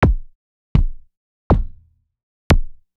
I mic the inside of the drum (not outward-facing skin) with an RE20, and also the beater (usually with a an SM57) to pick up the crack of the beater head.
Sure, here are four very different examples using the same technique. Four synthetic drum sounds from my ARP 2600 MkIII, layered with acoustic kick drums recordings I made as described above (the latter were initially recorded for my Polyend factory sound set).
The first, third and fourth examples are focused on the synthetic kick, with the acoustic kicks underneath to reinforce them. The second example is the other way around, focusing on the acoustic sound, with the 2600 kick adding some oomph.
Kinda surprised how well 2 sits in some of my current noodlings, but haha oh wow that scifi laser blaster sharp pitch envelope in 4 is also pretty sweet!